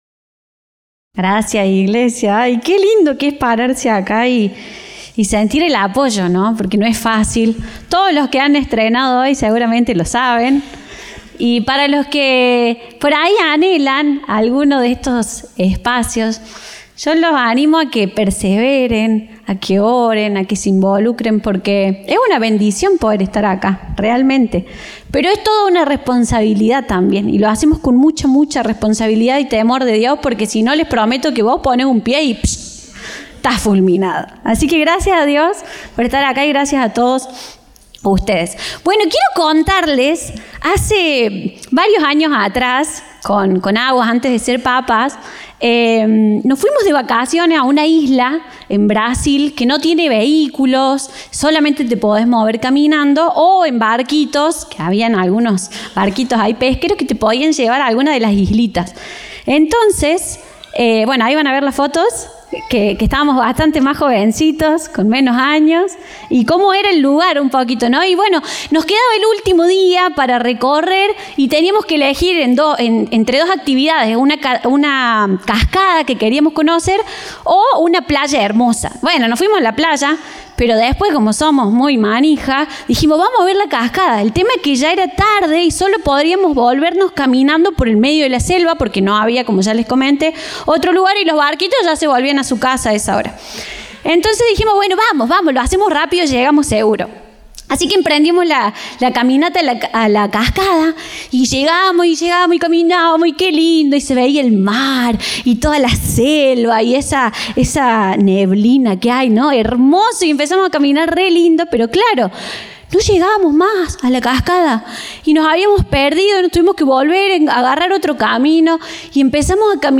Compartimos el mensaje del Domingo 2 de Noviembre de 2025 Orador invitado